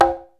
Rhythm Machine Sound "RX5"